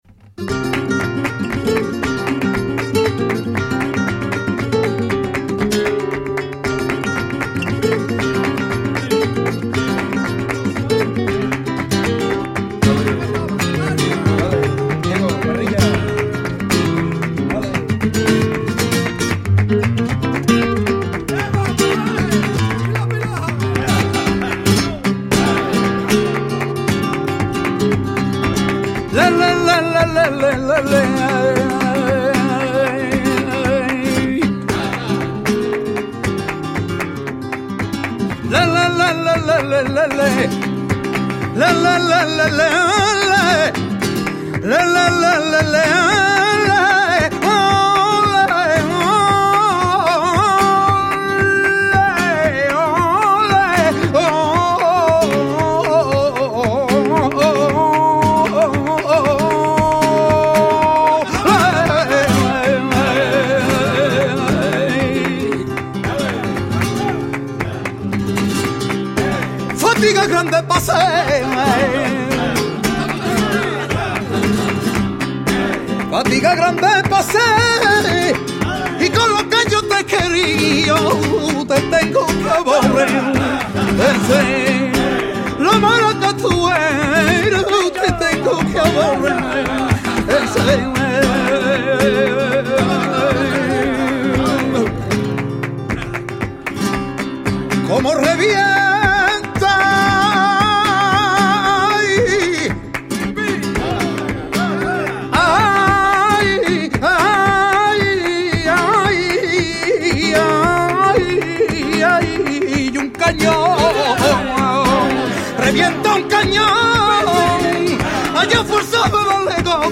bulerías
guitar accompaniment